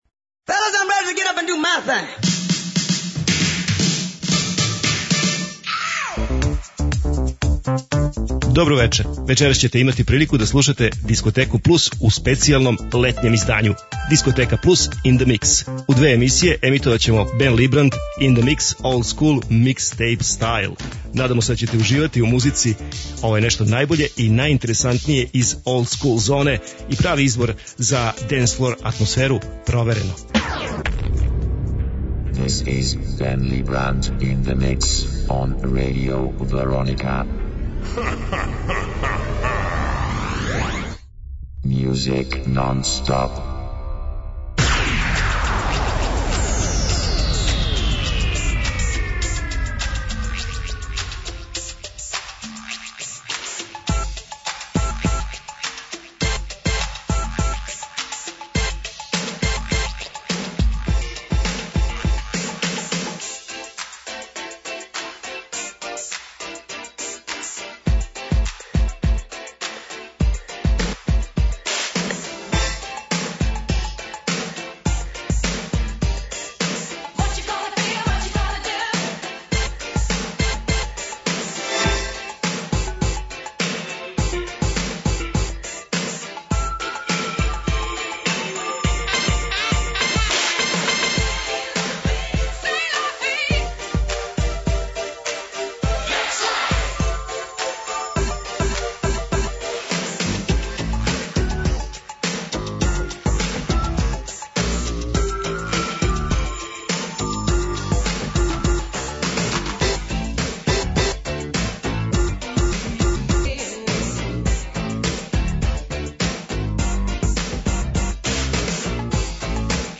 Eмисија посвећена најновијој и оригиналној диско музици у широком смислу.
Заступљени су сви стилски утицаји других музичких праваца - фанк, соул, РнБ, итало-диско, денс, поп. Сваке среде се представља најновија, актуелна, Топ 40 листа уз непосредан контакт са слушаоцима и пуно позитивне енергије.